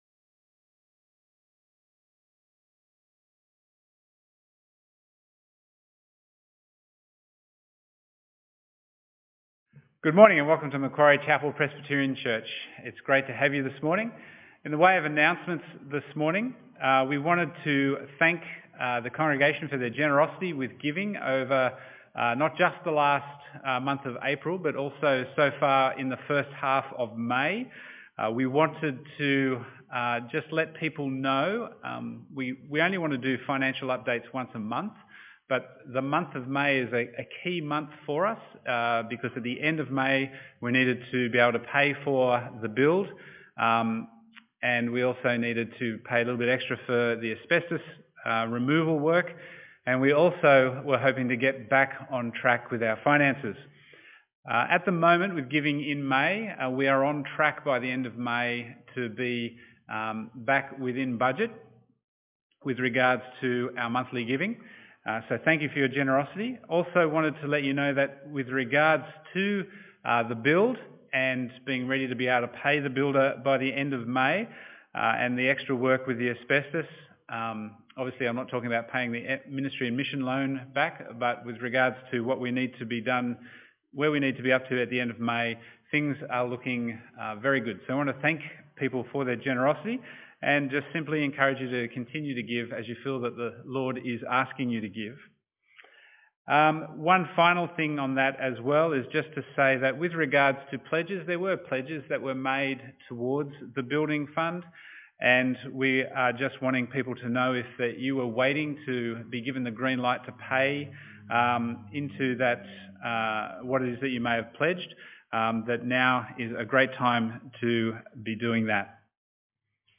Preacher